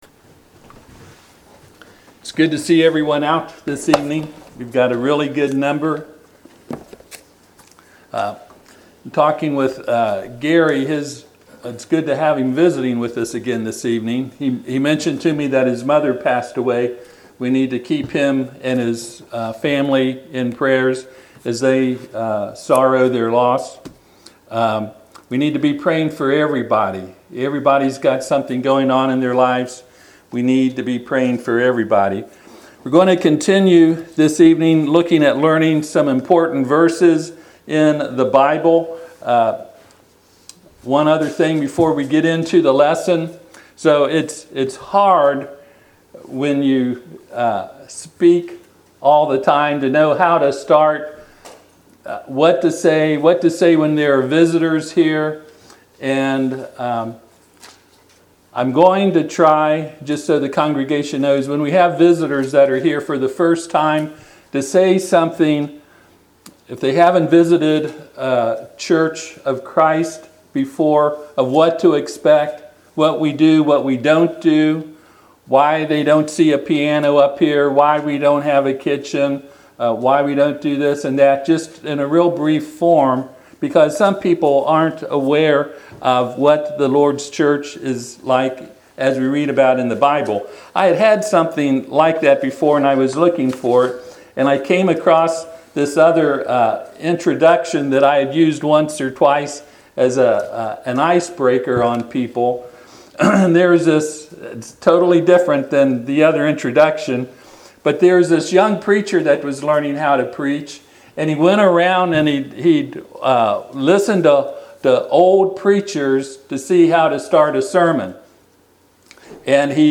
Passage: Job 22:22 Service Type: Sunday PM « Remember Lot’s Wife Can We Allow Our Conscience To Be Our Guide.